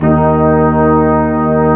Index of /90_sSampleCDs/Roland LCDP06 Brass Sections/BRS_Quintet/BRS_Quintet long